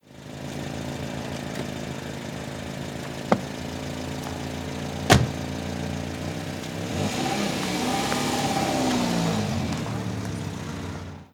Ralentí, abrir, cerrar puertas y salida de un coche
coche
ralentí
Sonidos: Transportes